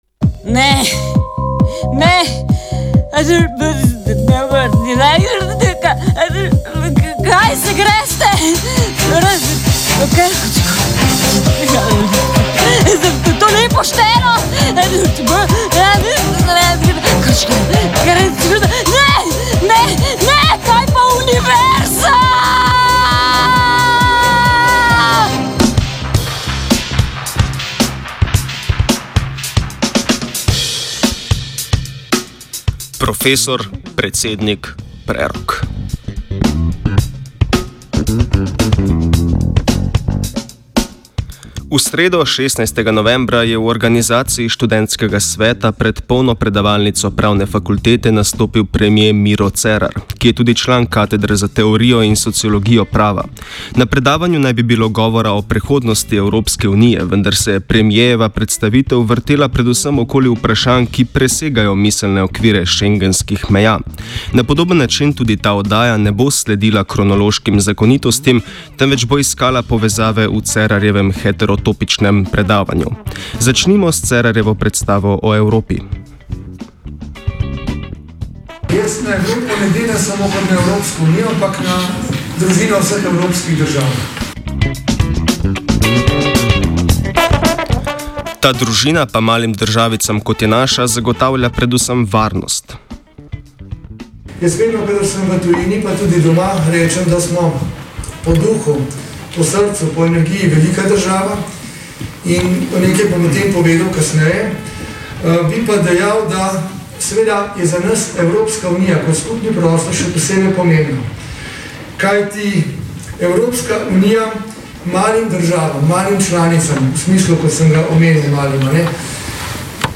V sredo, 16. novembra, je v organizaciji Študentskega sveta pred polno predavalnico Pravne fakultete nastopil premier Miro Cerar, ki je tudi član Katedre za teorijo in sociologijo prava.